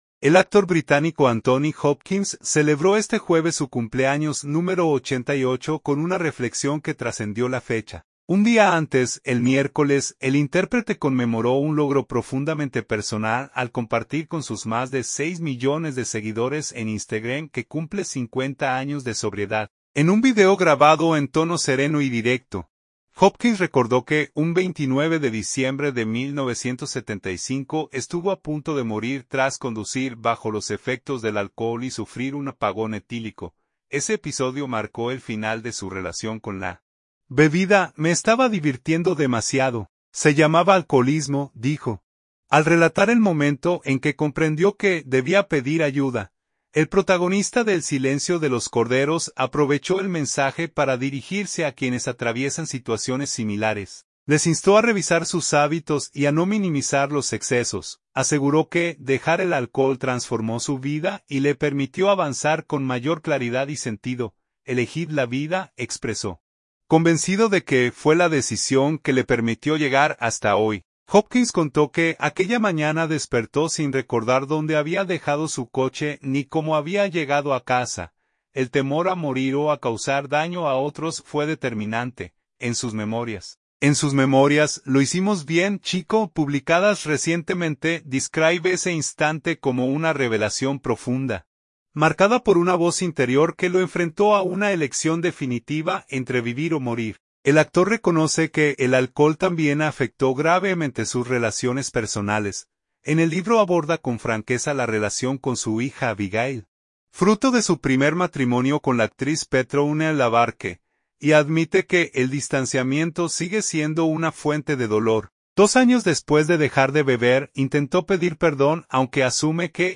En un video grabado en tono sereno y directo, Hopkins recordó que un 29 de diciembre de 1975 estuvo a punto de morir tras conducir bajo los efectos del alcohol y sufrir un apagón etílico.